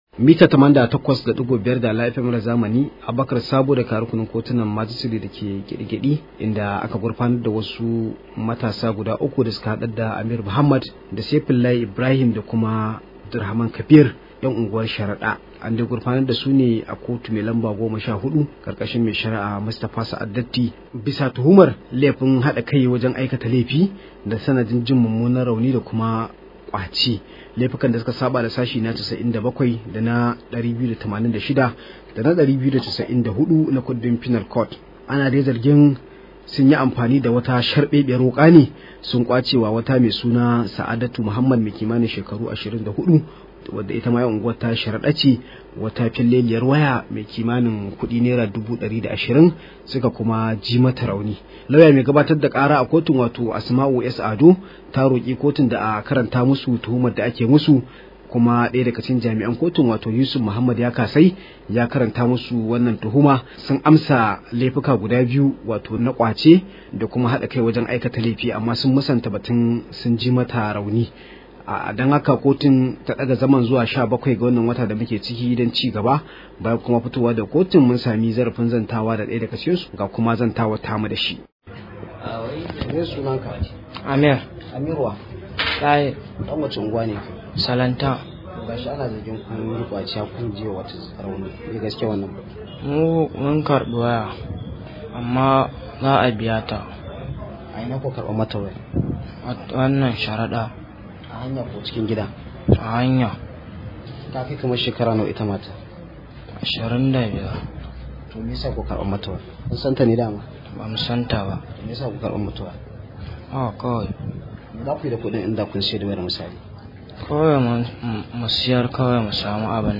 Rahoto: Matasa 3 sun gurfana a kotu kan zargin kwacen waya